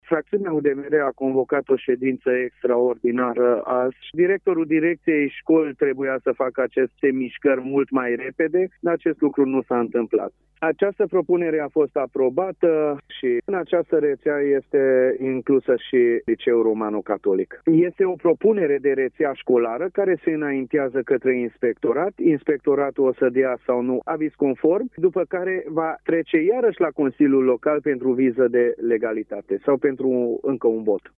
Liderul fractiunii UDMR din Consiliul Local Tg.Mureș, Csiki Zsolt: